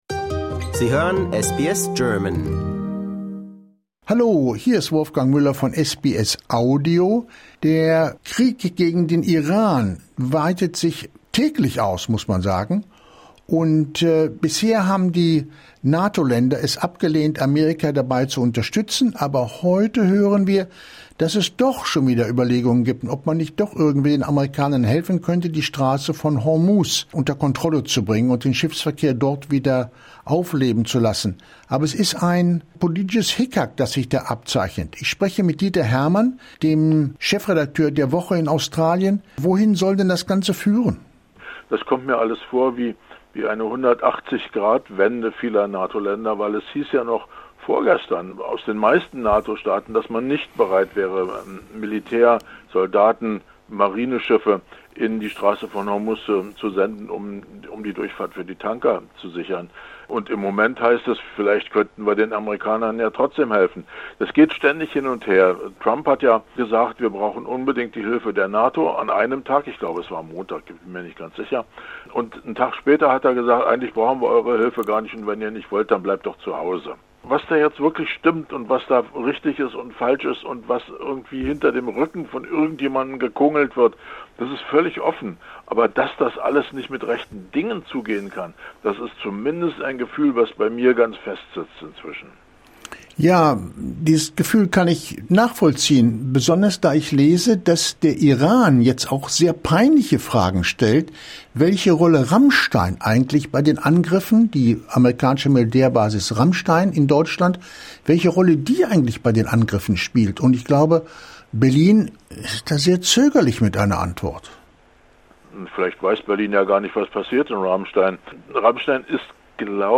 Disclaimer: We would like to point out that the opinions expressed in this article represent the personal views of the interviewed/interlocutor.